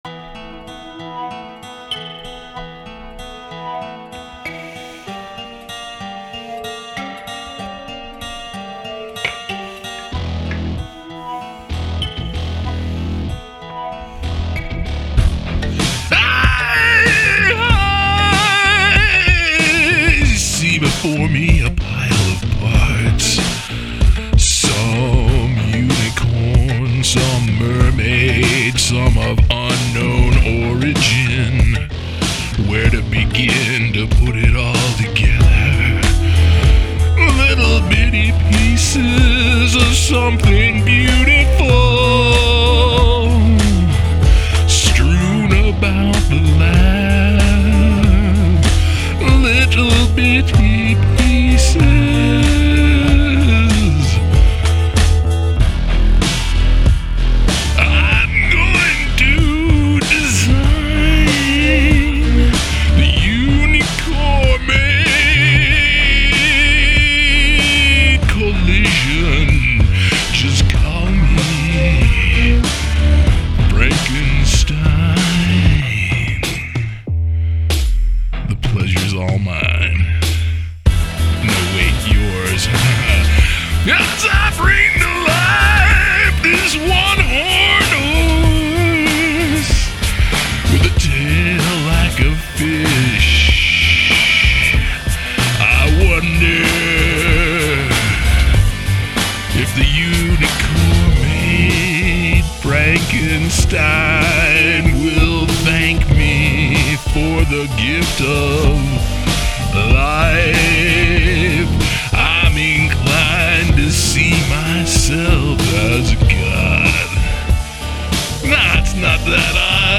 rockin’ heavy groove kind-o track!